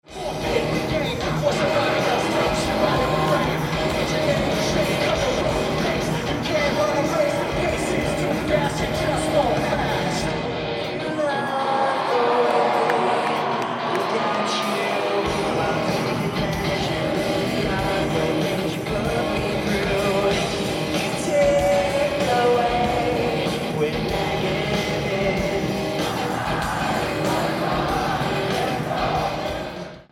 Venue:Kölnarena
Venue Type:Arena
• Location: Back of the hall.